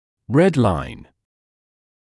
[red laɪn][рэд лайн]красная линия